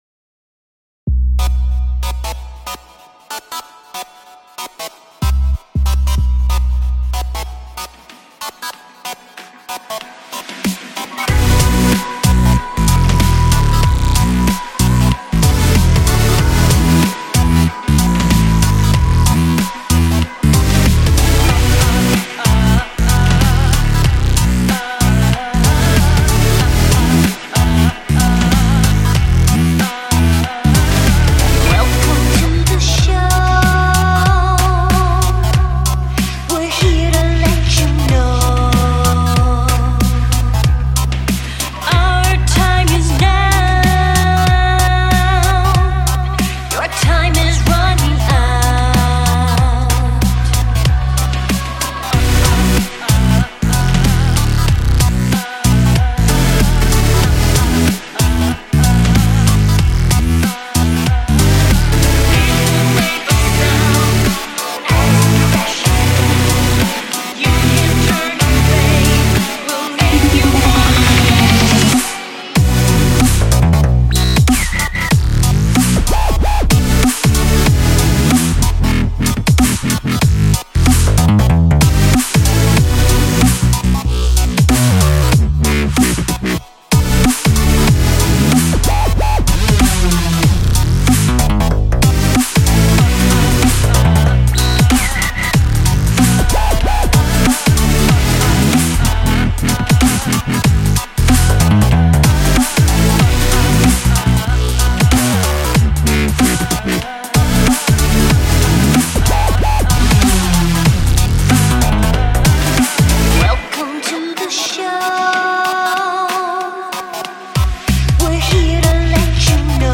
Glitch Hop | 94 BPM | Bm